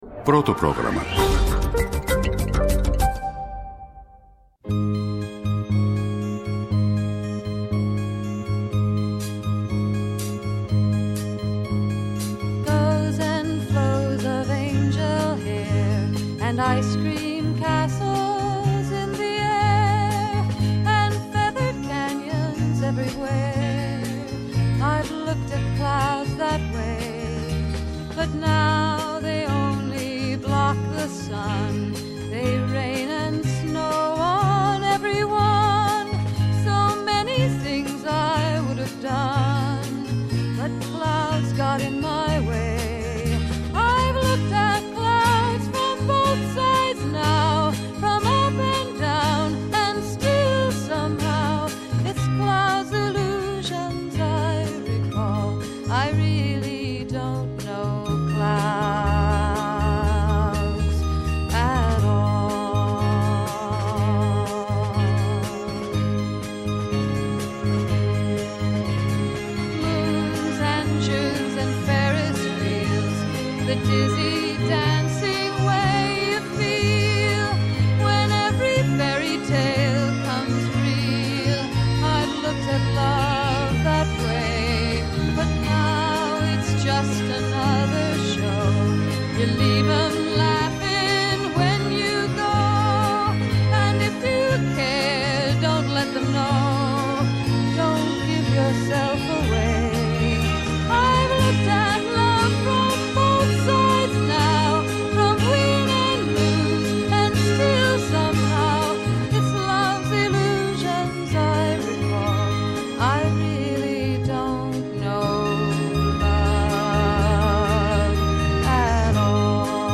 -O Αθανάσιος Τσαυτάρης, ομότιμος καθηγητής Γενετικής στο Αριστοτέλειο Πανεπιστήμιο Αθηνών (ΑΠΘ) και πρώην υπουργός Ανάπτυξης.